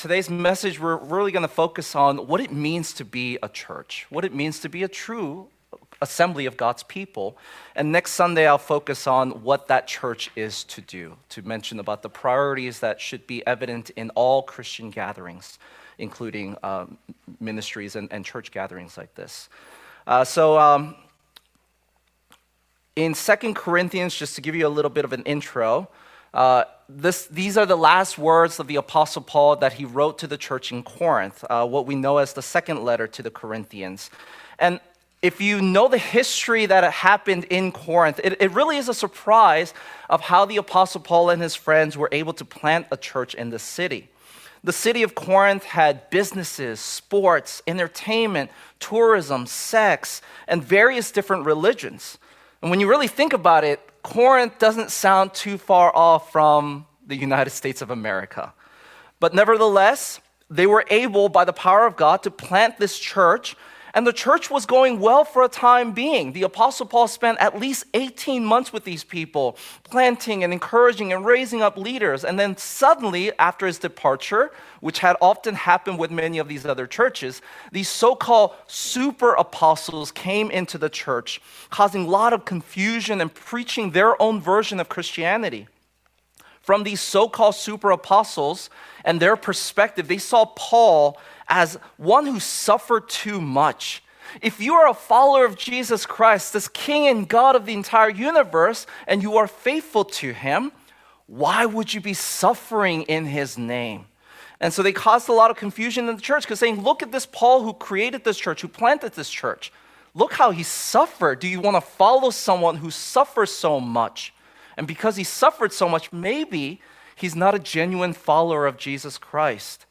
Theme of the church Passage: 2 Corinthians 13:11-14 Service Type: Lord's Day